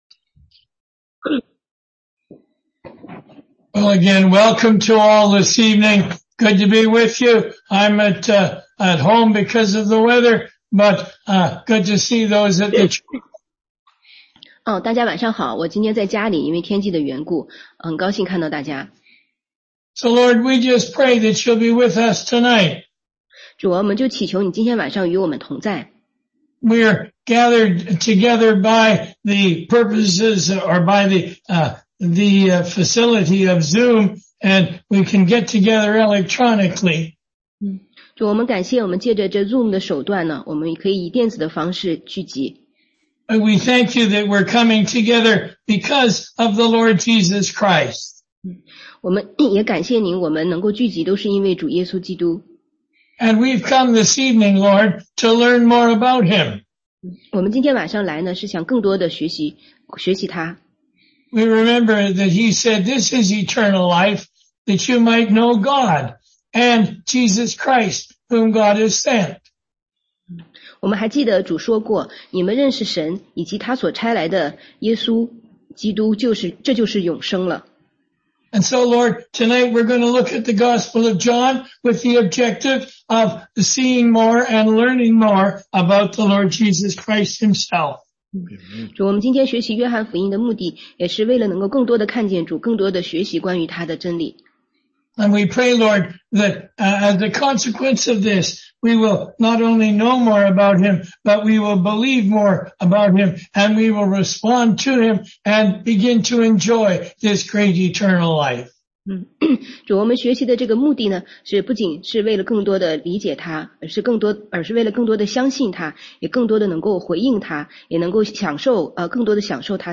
16街讲道录音 - 约翰福音解读—全书简介
中英文查经